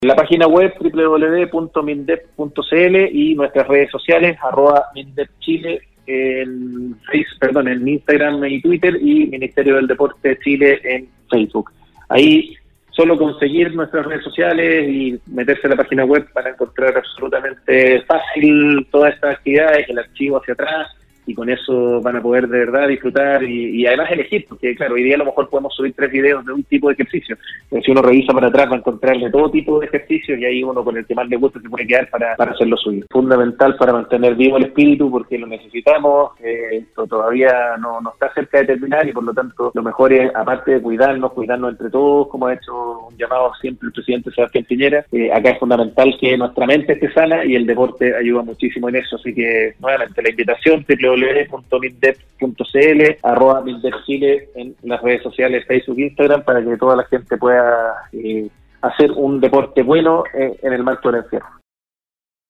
La mañana de este viernes, Andrés Otero, subsecretario del Deporte, sostuvo un contacto telefónico en el programa Al Día de Nostálgica, destacó la importancia del deporte como un beneficio para la salud, el espíritu, y en definitiva, para el bienestar de las personas.